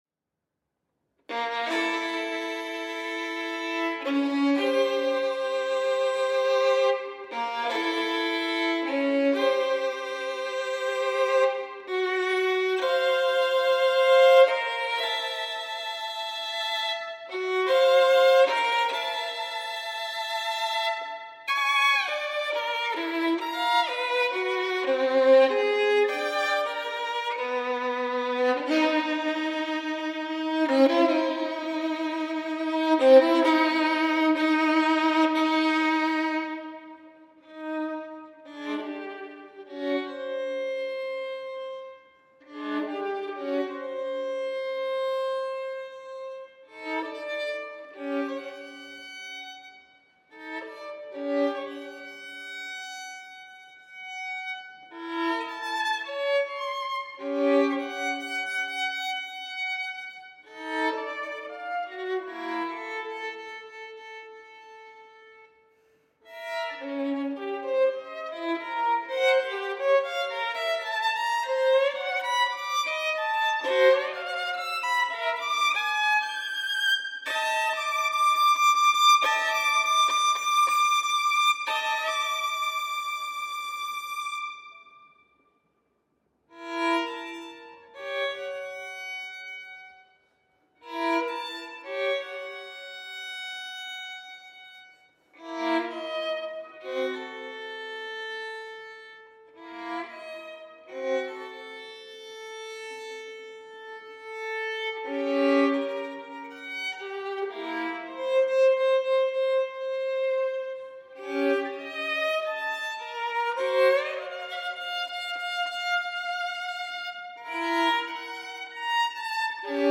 Contemporary Music from Iceland for Solo Violin